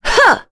Priscilla-Vox_Attack4.wav